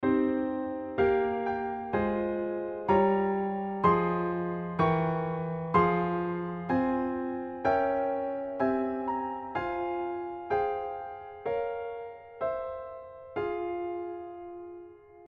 こちらはトップとベースの関係を反行のみにして作った音源。
広がる時には一斉に広がり、閉じる時には一斉に閉じるので、このようにカツオのようなフォルムになります。
• 上下の音幅がぐわっと広がったり閉じたりするため、ダイナミクス(強弱の幅)に優れている
2-VII_piano_allcontrary.mp3